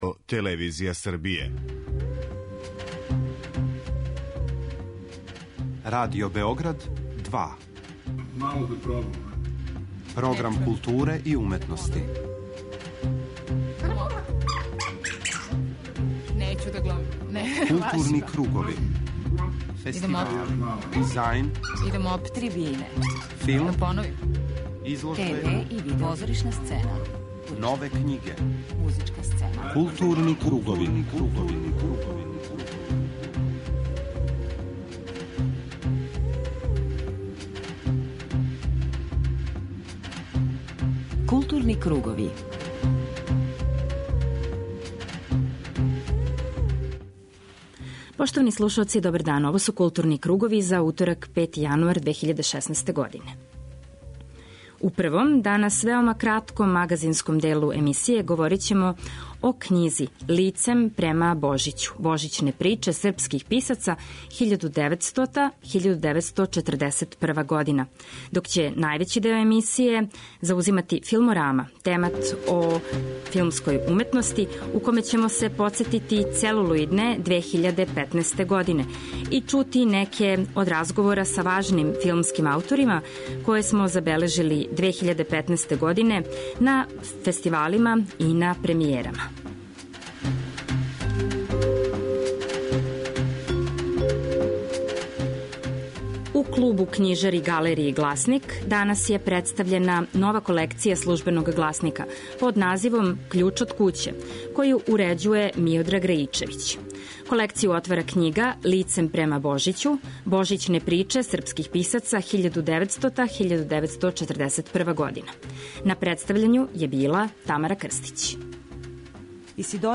У току протекле године угостили смо многе значајне филмске ауторе или са њима забележили разговоре на премијерама филмова, представљањима књига, филмским фестивалима у земљи и иностранству. У данашњој Филморами чућете неке од тих филмских разговора, међу којима ће бити онај о књизи Пјера Паола Пазолинија "Искусни момци", филму "Дивље приче", приказаном на Фесту, Фестивалу нитратног филма, али и многим домаћим филмским премијерама.